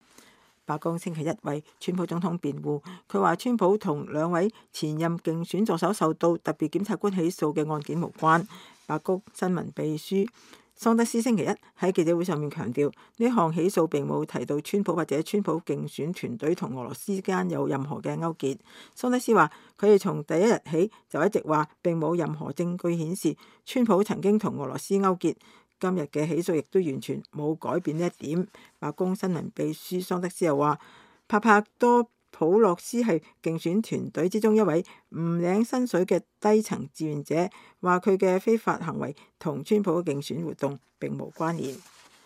白宮新聞秘書桑德斯星期一在記者會上